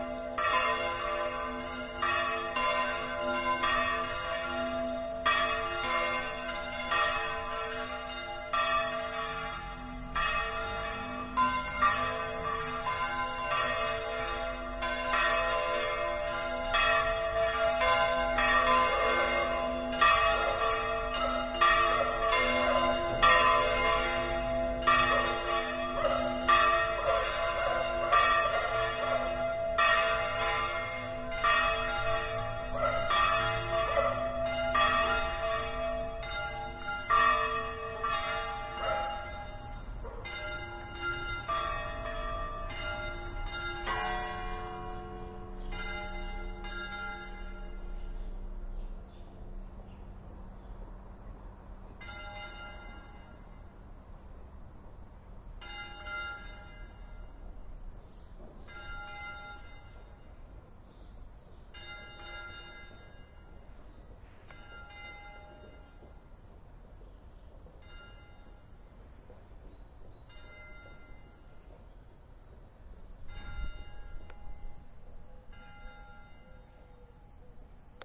描述：Church bell pealing, city noise and birds in background. Recorded in downtown Saluzzo (Piamonte, N Italy), using PCMM10 recorder with internal mics
标签： bells church pealing ambiance fieldrecording city
声道立体声